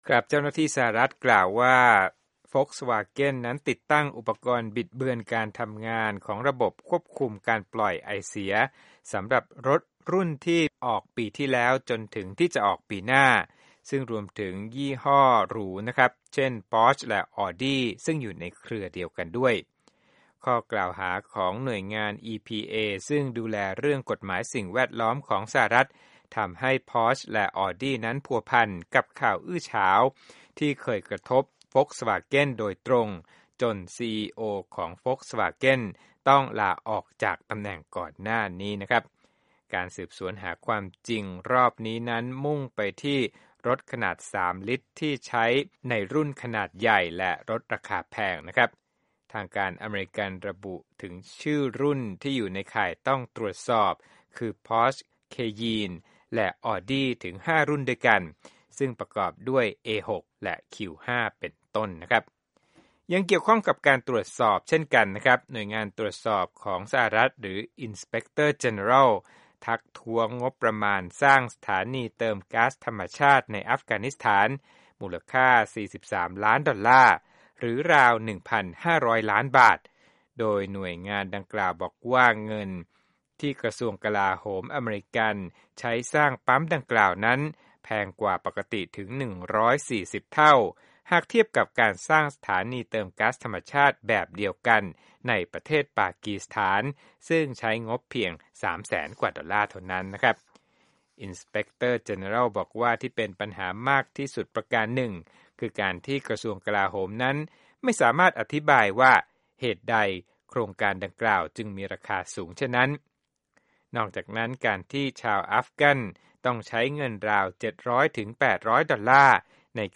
ธุรกิจ